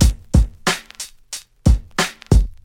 • 91 Bpm 90's Hip-Hop Breakbeat Sample A Key.wav
Free drum groove - kick tuned to the A note. Loudest frequency: 1167Hz
91-bpm-90s-hip-hop-breakbeat-sample-a-key-kmE.wav